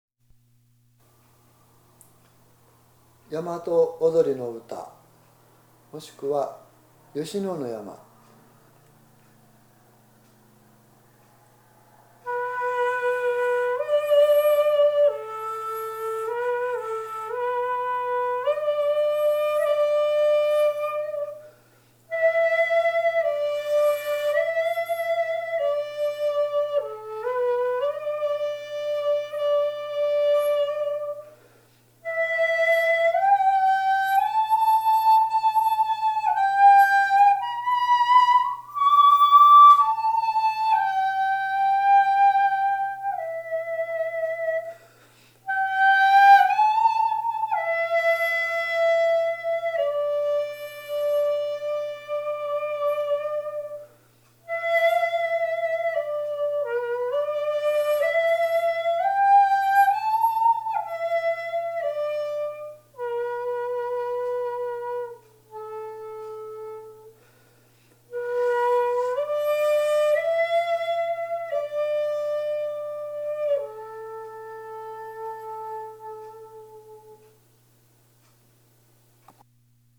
その内、「歌のない歌謡曲」扱いで一節切の独奏でもよく吹かれたようでした。
また私の復元演奏に合わせて歌っていただくと、300余年前の江戸前期の歌がよみがえります。